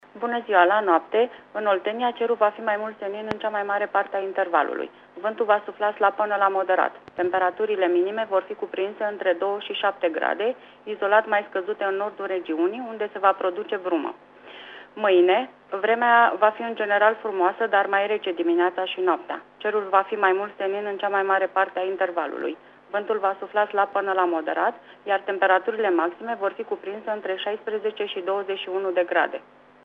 Prognoza meteo 4/5 octombrie (audio)